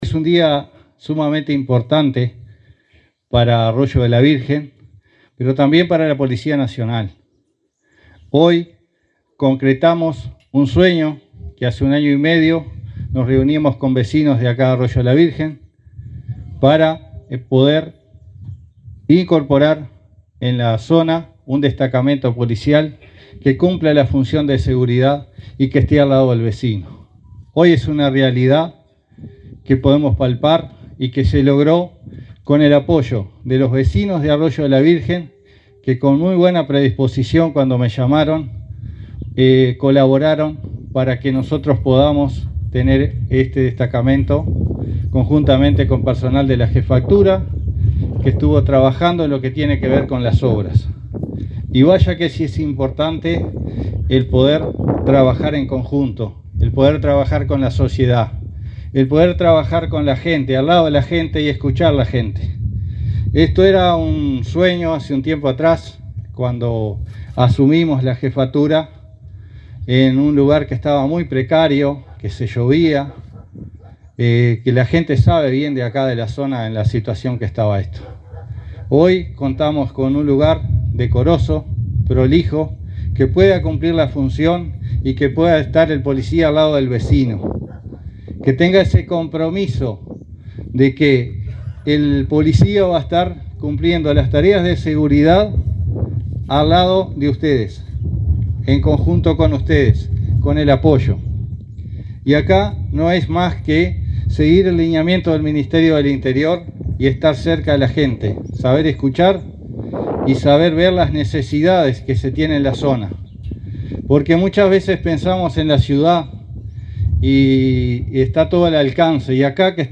Palabras del jefe de Policía de San José, Atilio Rodríguez
El jefe de Policía de San José, Atilio Rodríguez, se expresó, durante el acto de reinauguración del destacamento de Arroyo de la Virgen, en ese